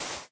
sand3.ogg